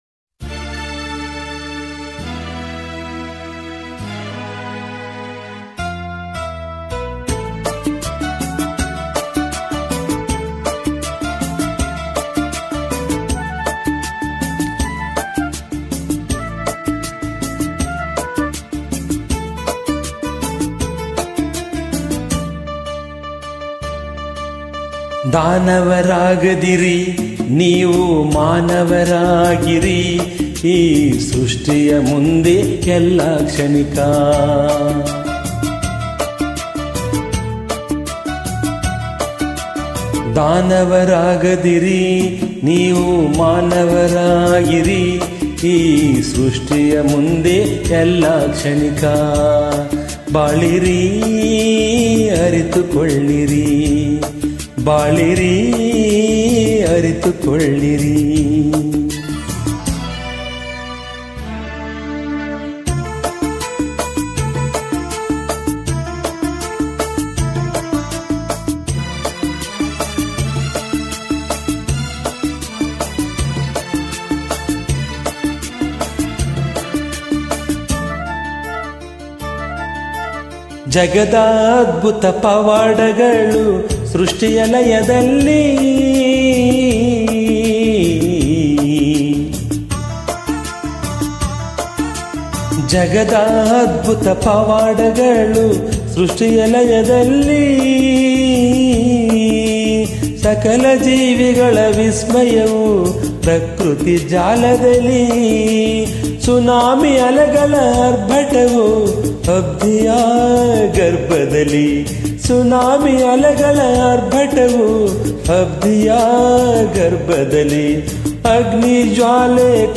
ಗಾಯನ